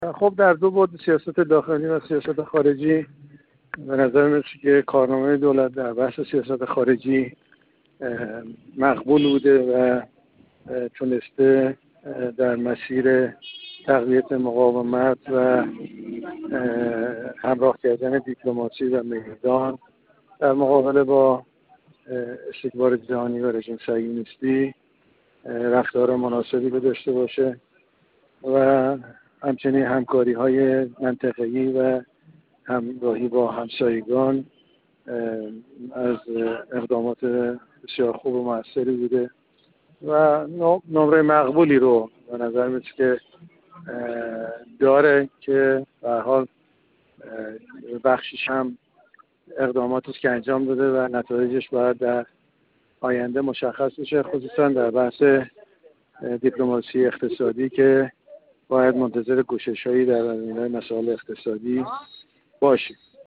کارشناس مسائل سیاسی
گفت‌وگو